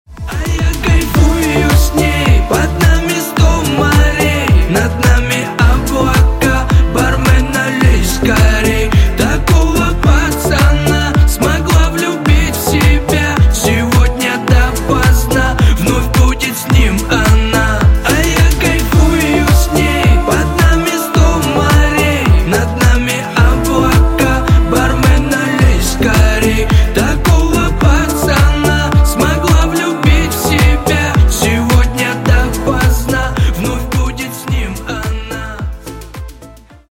# Поп Рингтоны